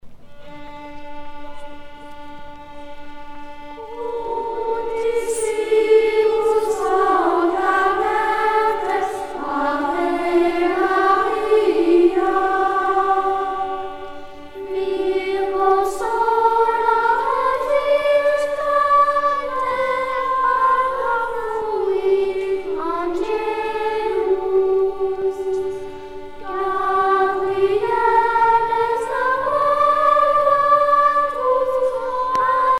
virelai à une seule voix
Pièce musicale éditée